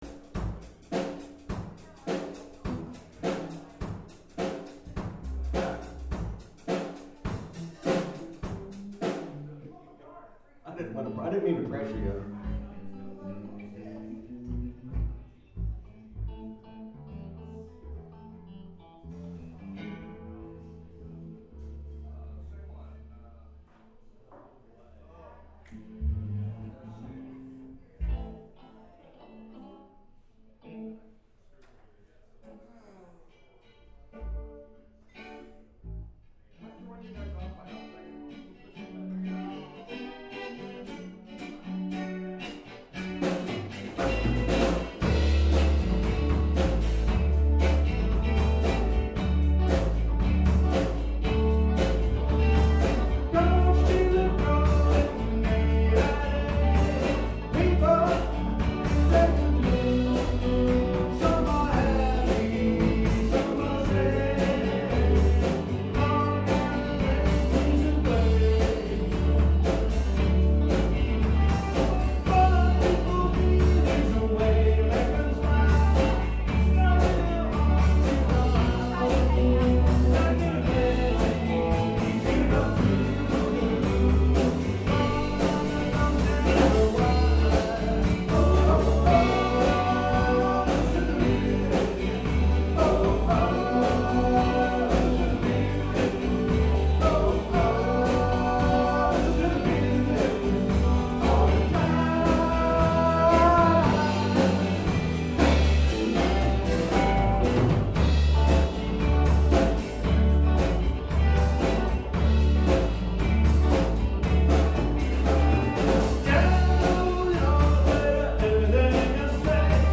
Bad Motor Scooter Band performs at Jollie's Lounge, April 11, 2014